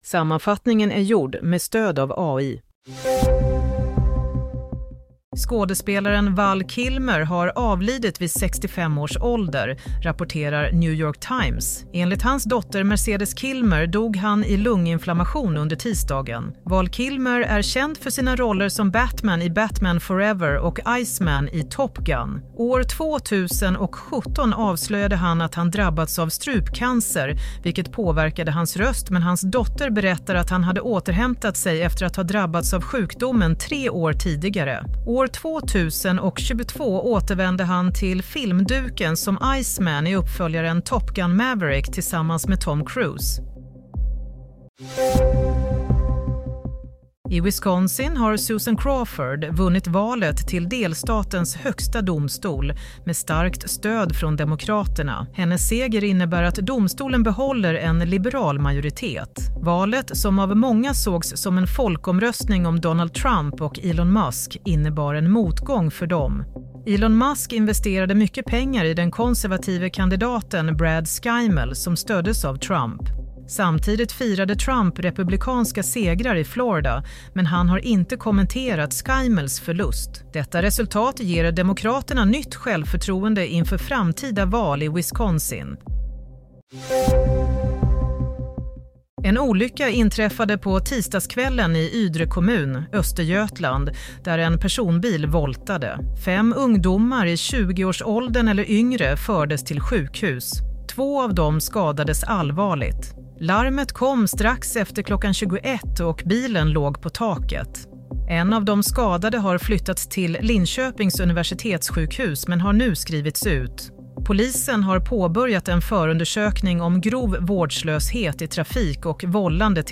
Play - Nyhetssammanfattning - 2 april 07:00
Sammanfattningen av följande nyheter är gjord med stöd av AI.